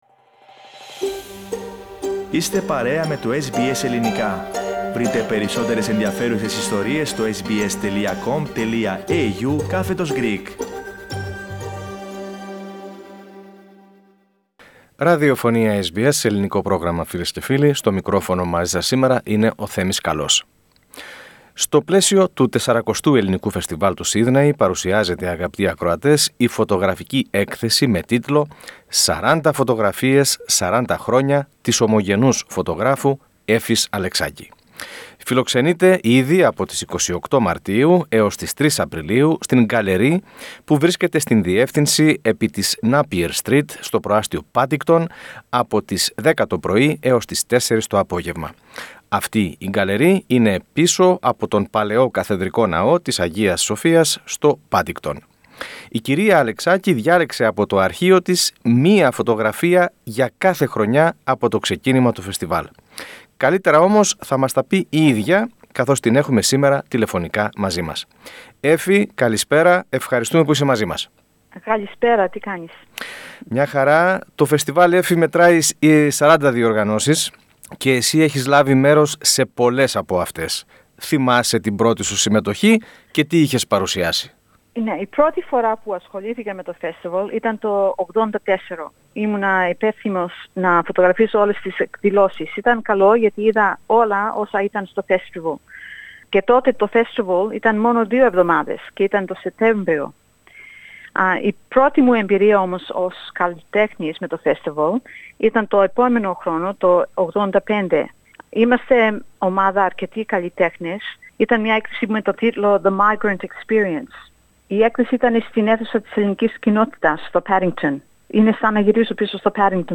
SBS Greek View Podcast Series